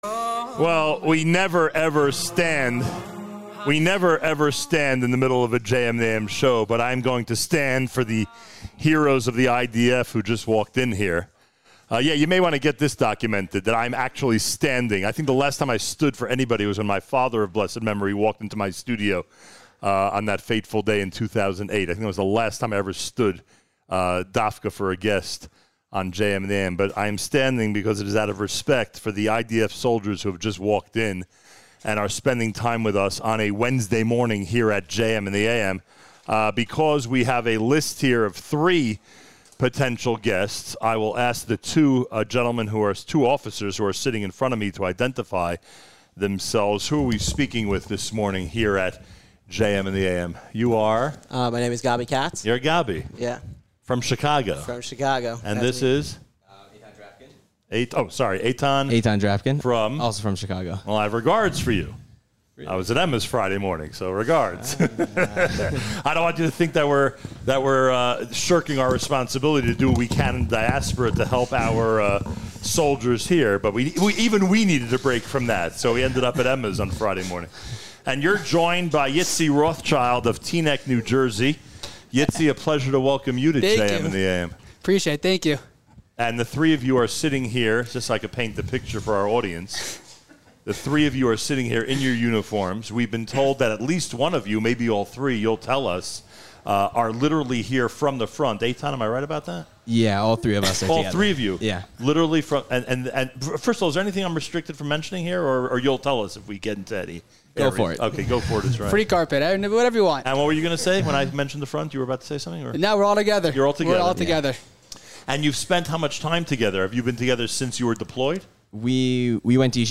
Live at Yeshivat HaKotel in Jerusalem